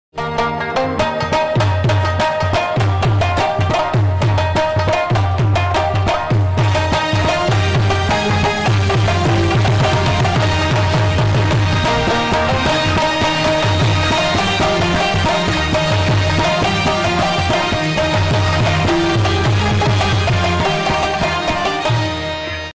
Pashto Rabab Music